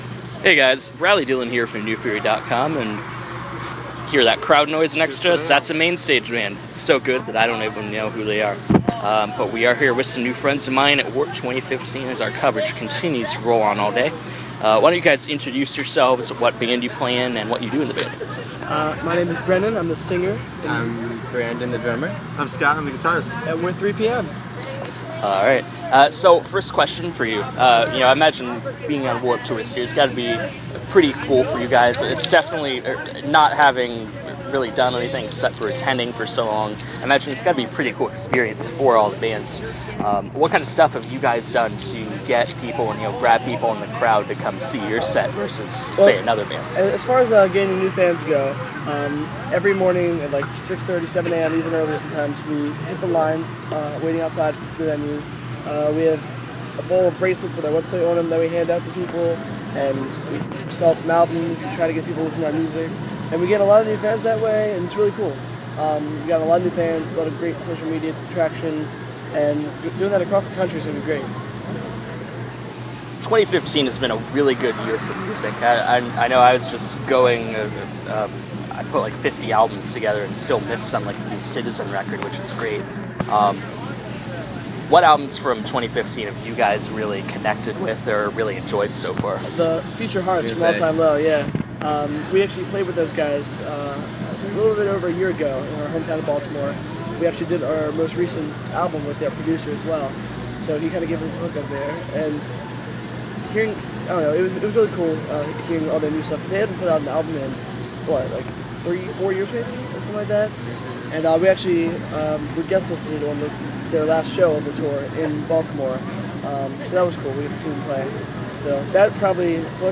Interview: 3PM
I caught up with the band in St Pete a few weeks ago, where we talked about the Warped experience, working the catering area, and how they’ve managed to gain so many new fans on tour this summer.